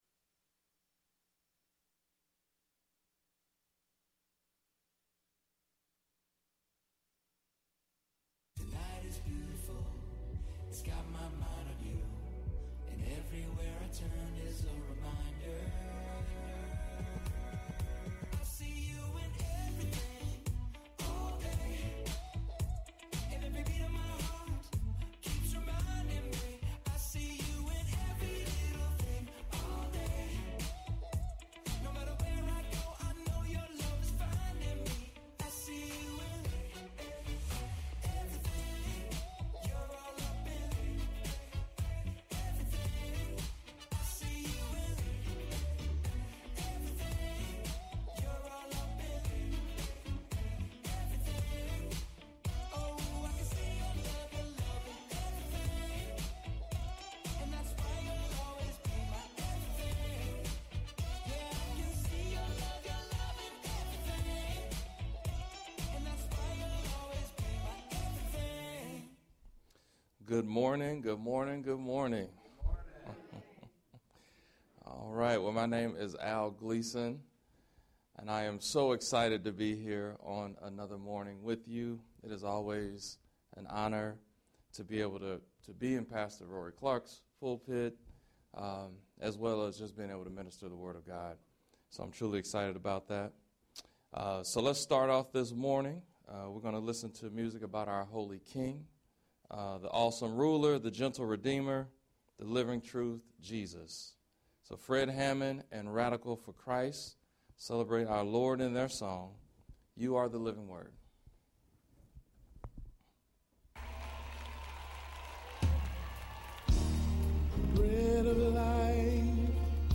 Guest Teacher